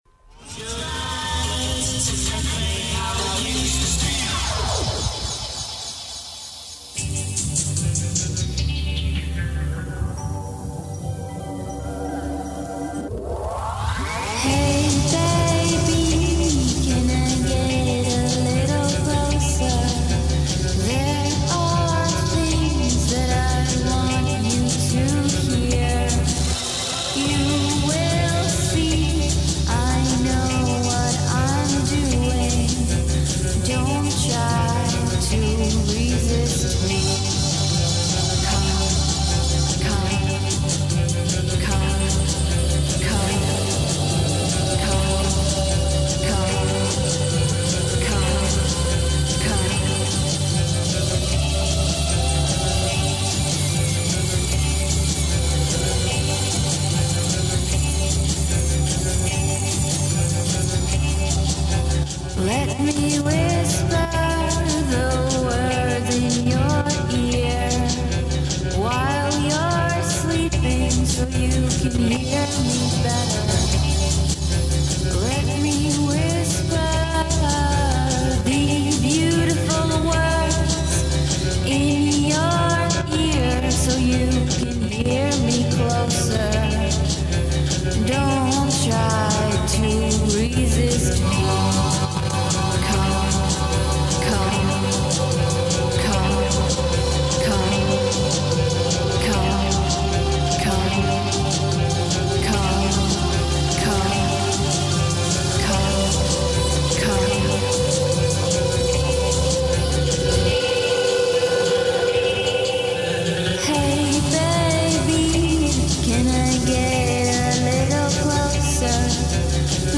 Catchy Uptempo Music